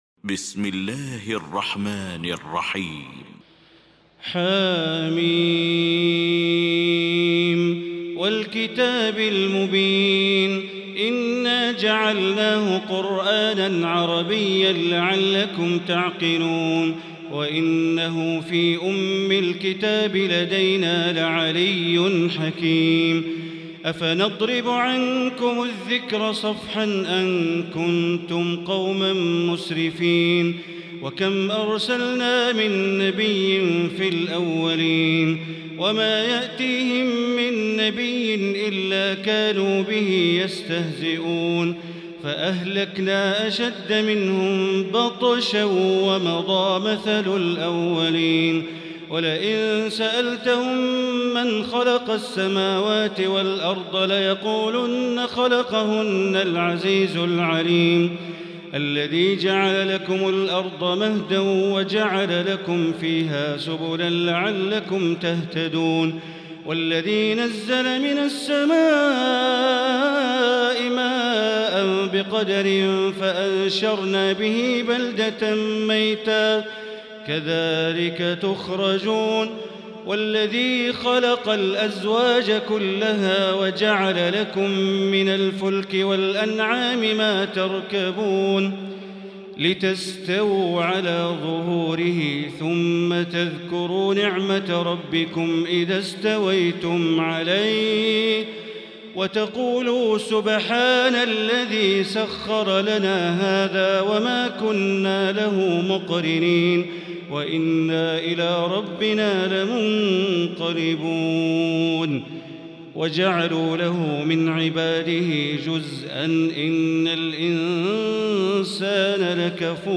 المكان: المسجد الحرام الشيخ: فضيلة الشيخ د. الوليد الشمسان فضيلة الشيخ د. الوليد الشمسان فضيلة الشيخ ياسر الدوسري الزخرف